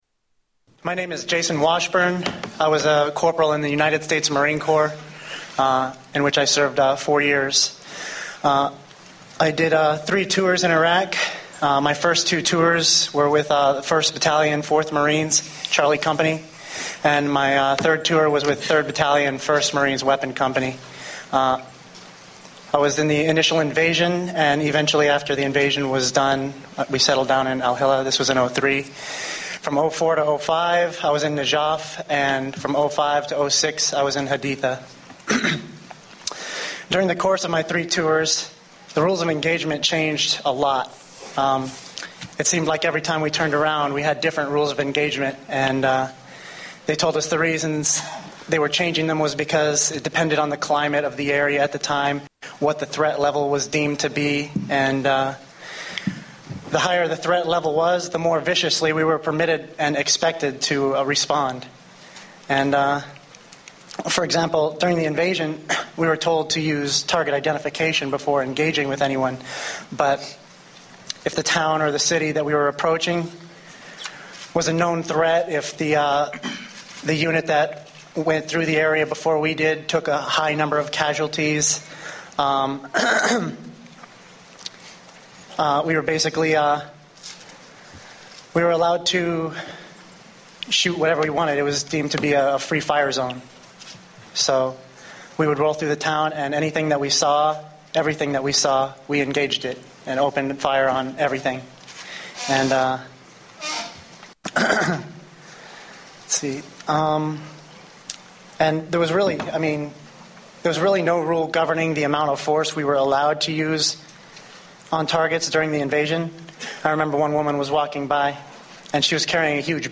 Tags: Historical Top 10 Censored News Stories 2009 Censored News Media News Report